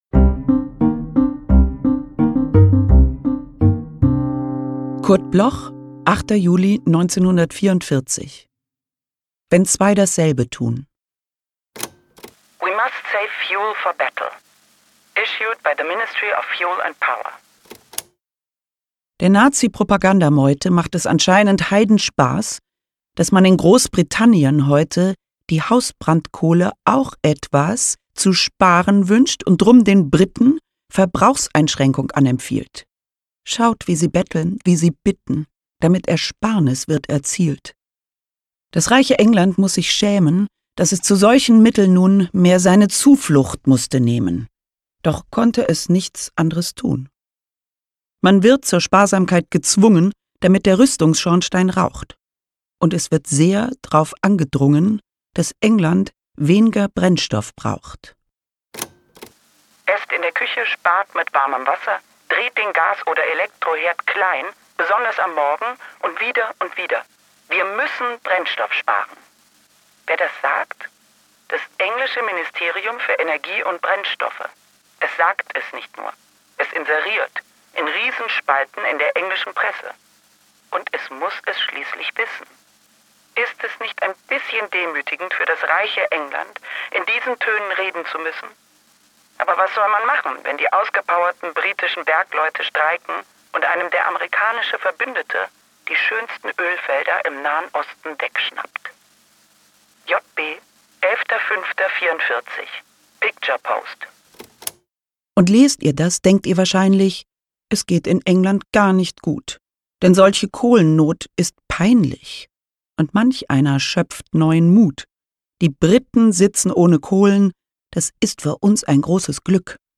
voorgedragen door Caroline Peters
Caroline-Peters-Wenn-zwei-dasselbe-tun-…_mit-Musik.m4a